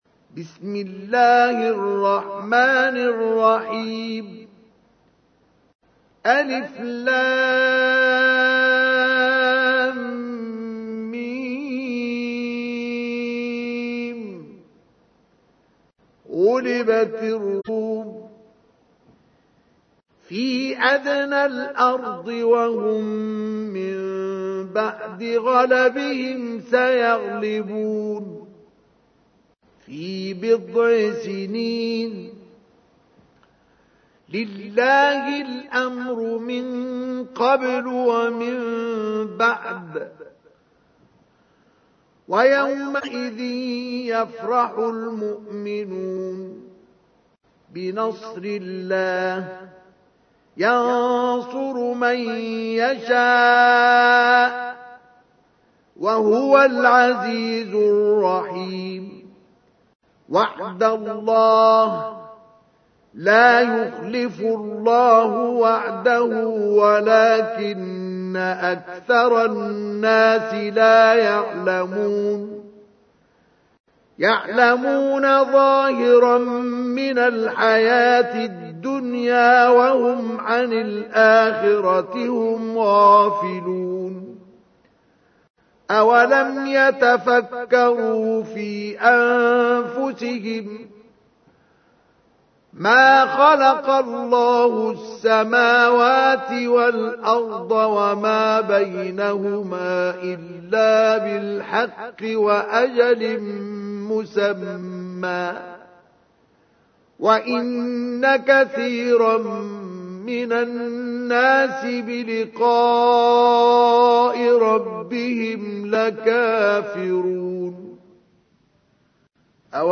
تحميل : 30. سورة الروم / القارئ مصطفى اسماعيل / القرآن الكريم / موقع يا حسين